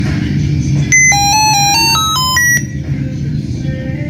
【求助】跪求各位大侠有人听过这么美妙的报警音乐吗？